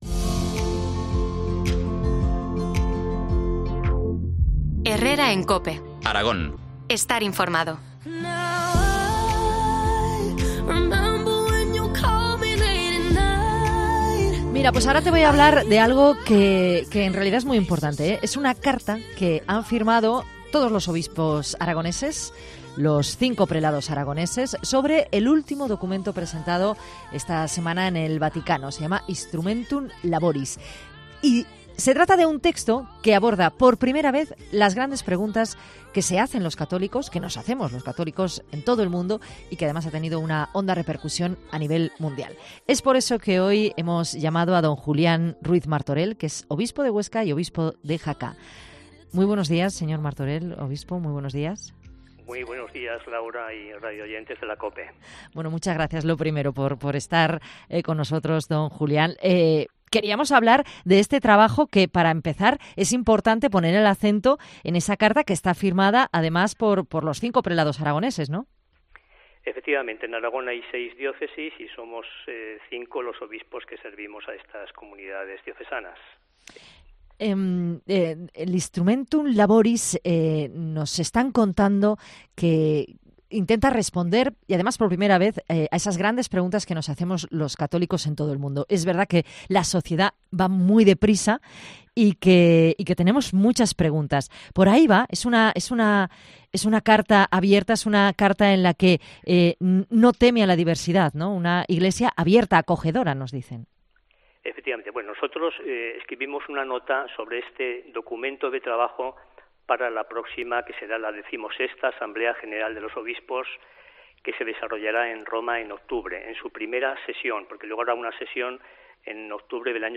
Entrevista al Obispo de Huesca y de Jaca, Monseñor Julián Ruiz Martorell.